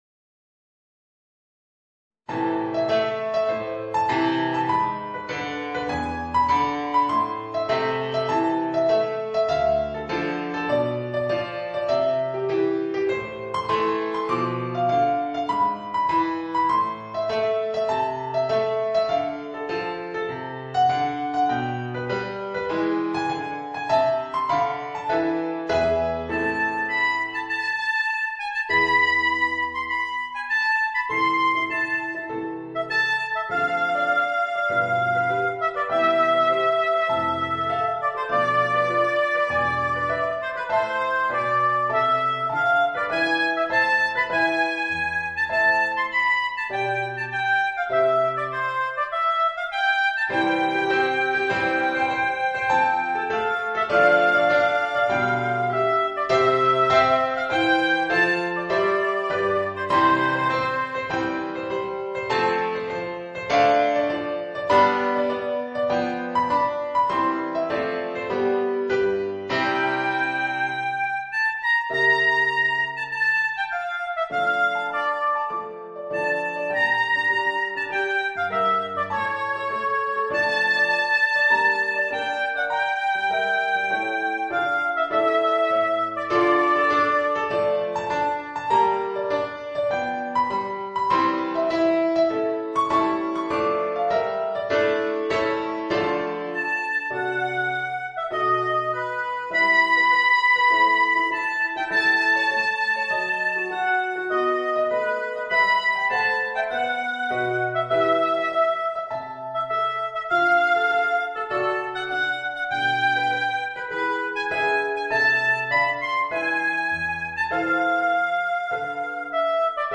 Voicing: Oboe and Organ